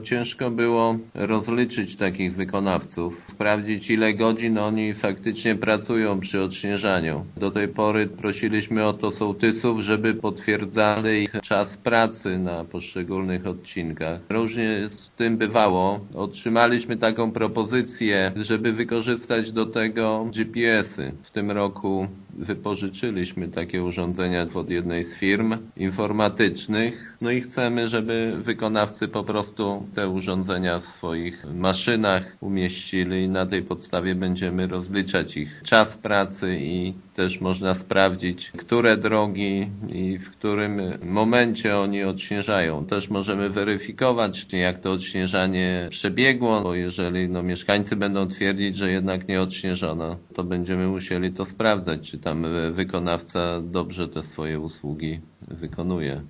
„Wierzymy, że zastosowanie tego rozwiązania będzie bardziej sprawiedliwe i wiarygodne od metod, którymi do tej pory się posługiwaliśmy” – mówi w rozmowie z Informacyjną Agencją Samorządową wójt gminy Głusk Jacek Anasiewicz: